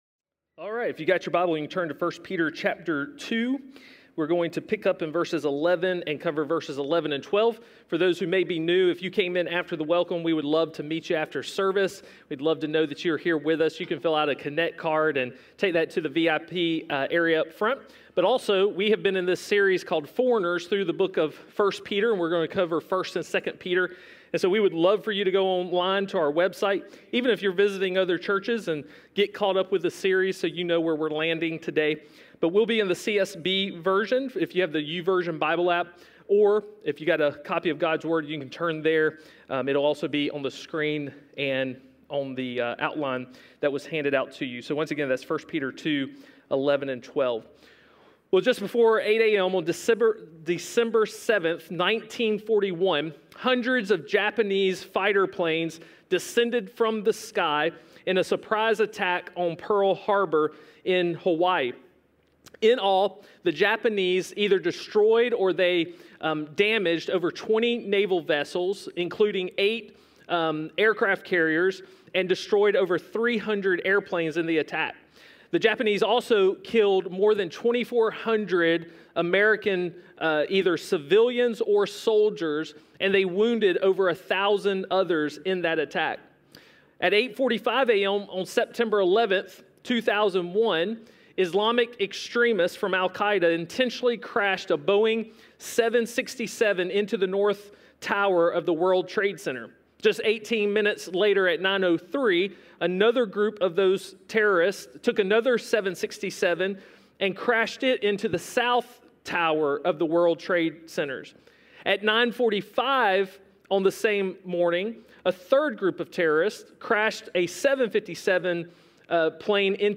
A message from the series "Foreigners."